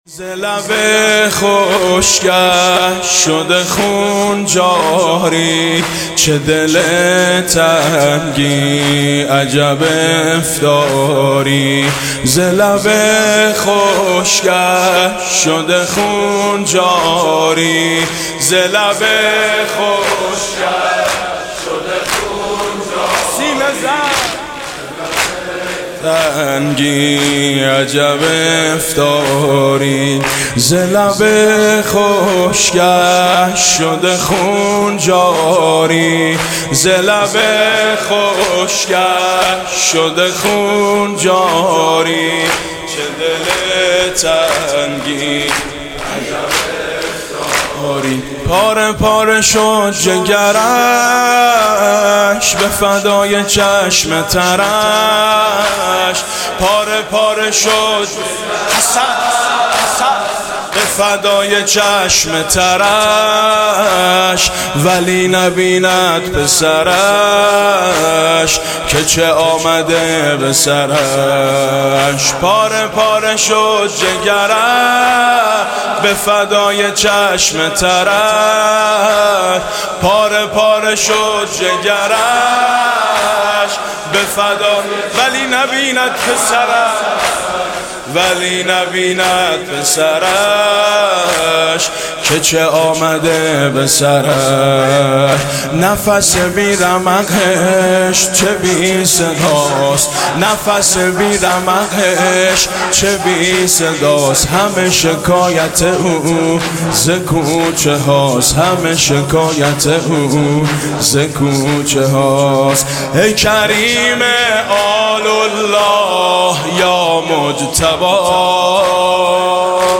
«شهادت امام حسن 1393» زمینه: ز لب خشکش شده خون جاری